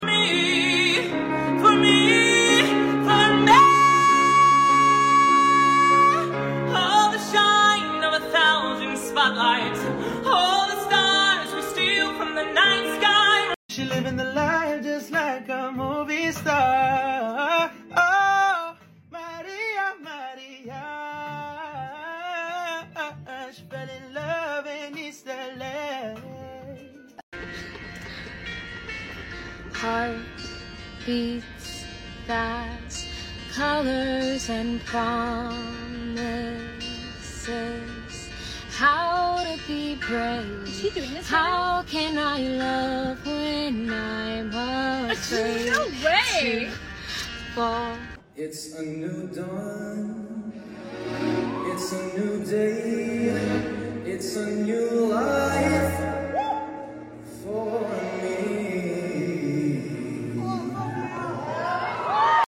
Ranking UNEXPECTED SINGING voices! 🤩 sound effects free download